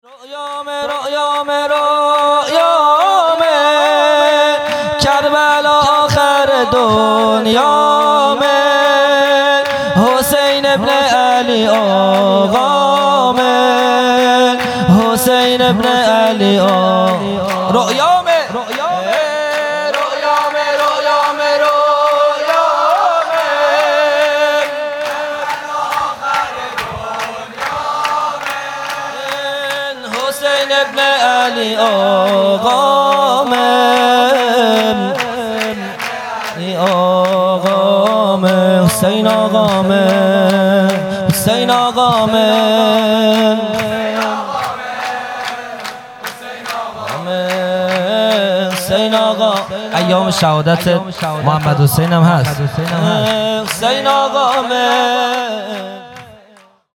سرود | کربلا آخر دنیامه
جلسۀ هفتگی (به مناسبت ولادت رسول اکرم(ص) و امام صادق(ع) | به یاد شهید تهرانی مقدم | 23 آبان 1398